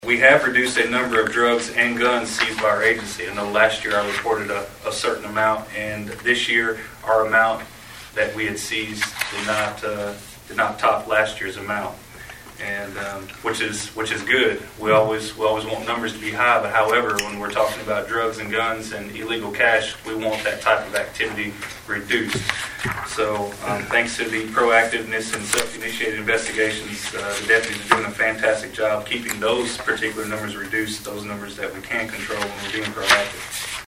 And that’s according to Sheriff Aaron Acree, who Monday night at fiscal court gave a comprehensive year-in-review to Trigg County’s magistrates and their judge-executive, Stan Humphries.